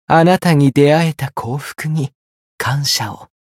觉醒语音 感谢与您相遇的这份幸福 あなたに出会えた幸福に、感謝を 媒体文件:missionchara_voice_475.mp3